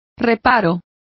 Complete with pronunciation of the translation of qualm.